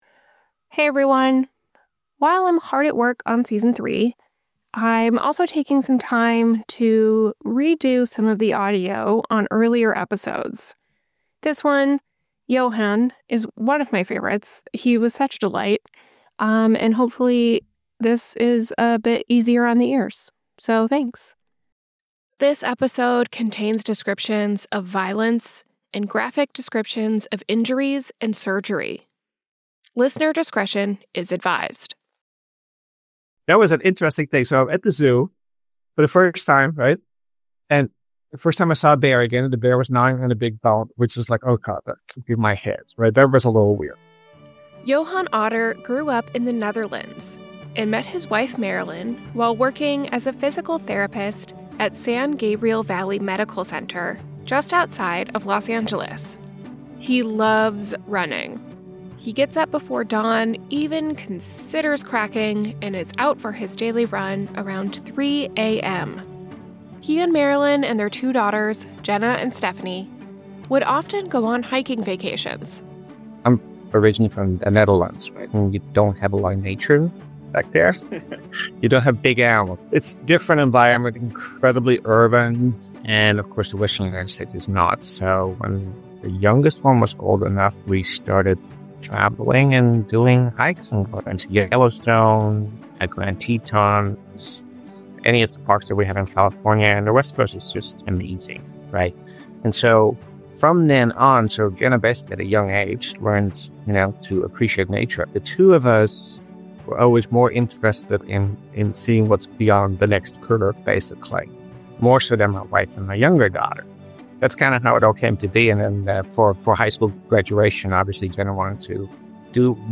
While the show is on hiatus, I'm re-releasing some episodes with better audio quality.